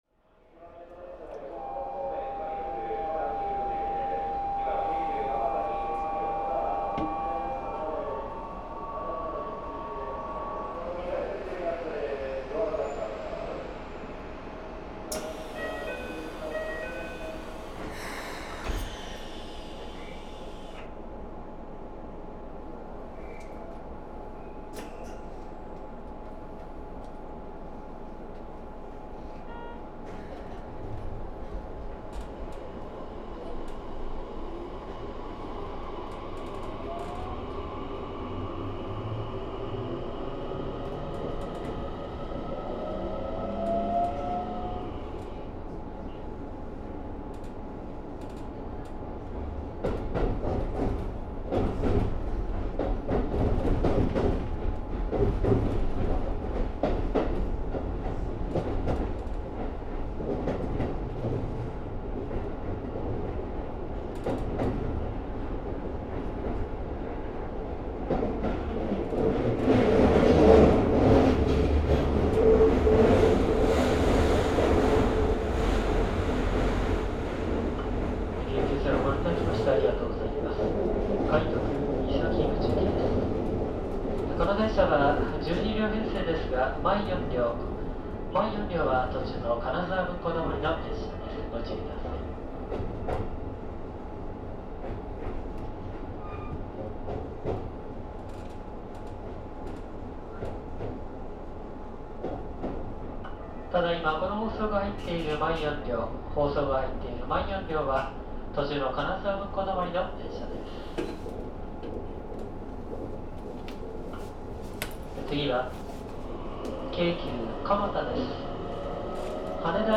・走行音(1～3次車・1C8M東洋(全区間))(115MB★)
収録区間：本線(特急) 泉岳寺→三崎口
制御方式：VVVFインバータ制御(東洋・三菱GTO後期) 1994(平成6)年に登場した車両。
東洋製は1～3次車と4次車どちらも標準的な東洋インバーターの音であるが、両者では変調のタイミングや音程に差がある。